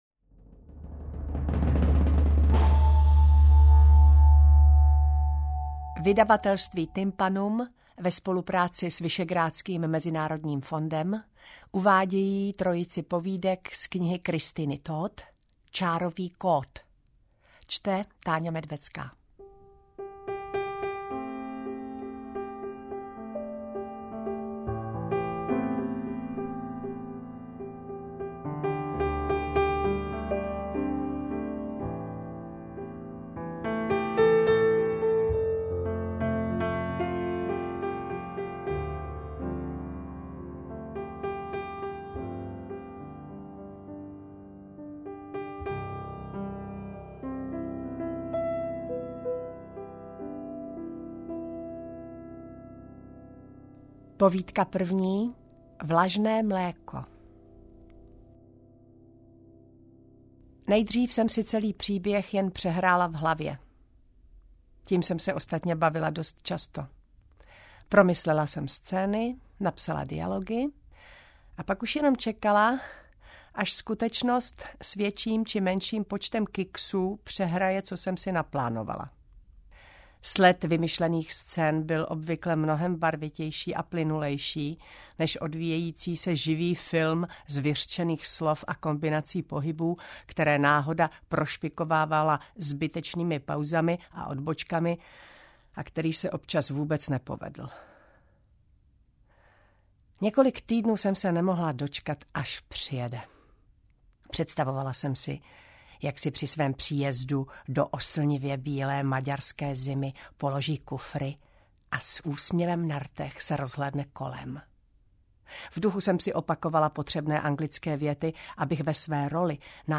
Interpret:  Taťjána Medvecká
AudioKniha ke stažení, 3 x mp3, délka 1 hod. 11 min., velikost 65,7 MB, česky